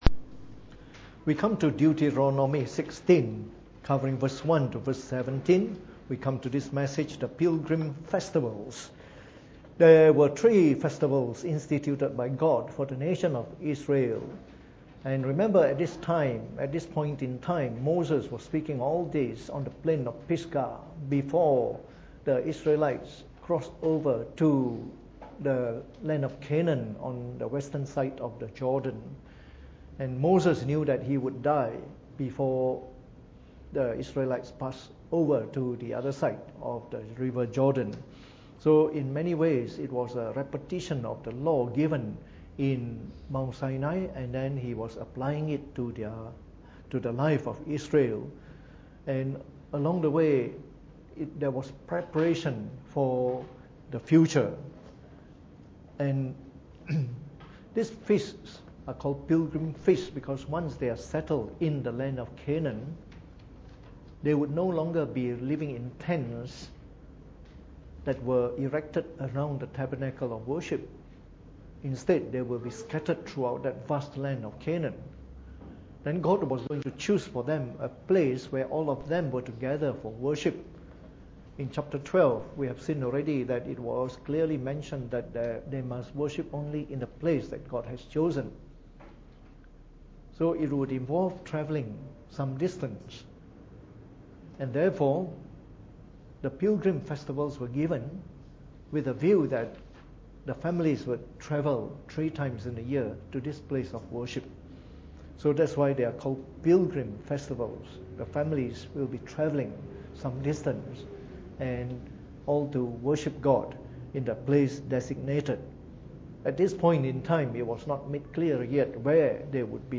Preached on the 23th of May 2018 during the Bible Study, from our series on the book of Deuteronomy.